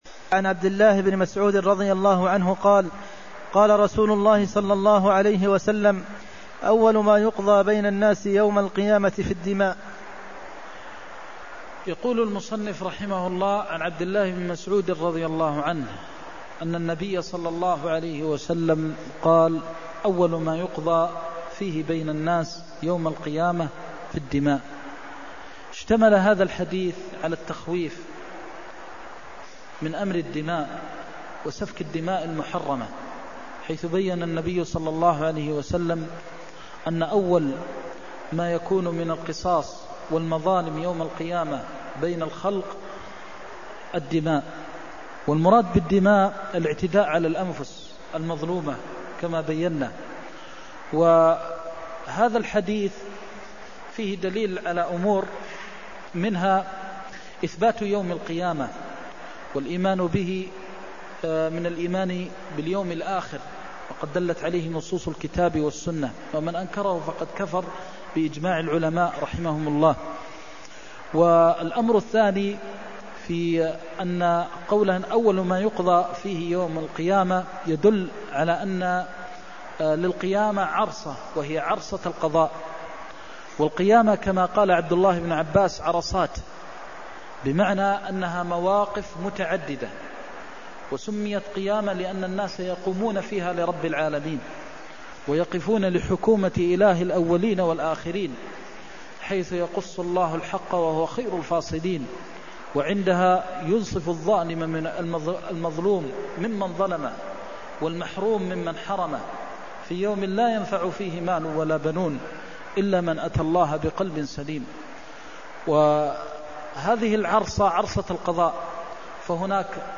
المكان: المسجد النبوي الشيخ: فضيلة الشيخ د. محمد بن محمد المختار فضيلة الشيخ د. محمد بن محمد المختار أول ما يقضى بين الناس يوم القيامة في الدماء (320)